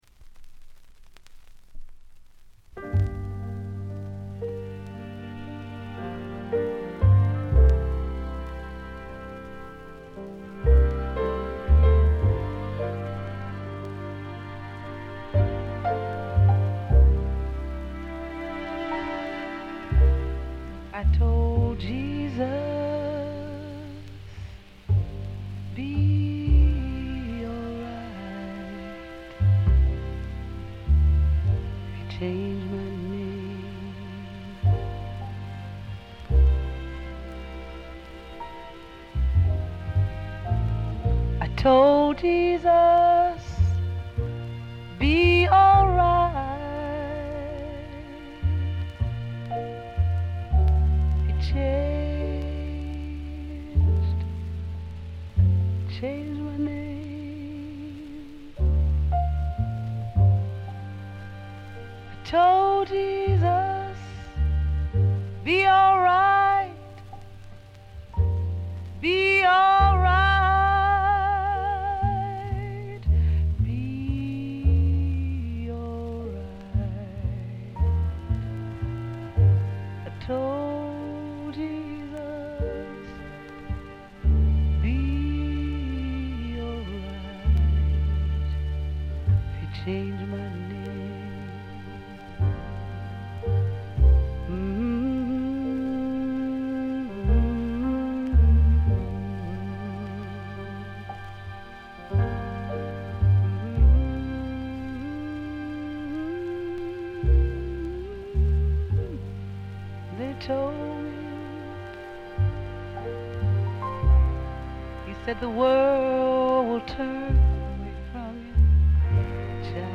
軽微なバックグラウンドノイズのみ。
才媛ぶりを見事に発揮したジャズ色の強いアルバム。
試聴曲は現品からの取り込み音源です。
Piano, Vocals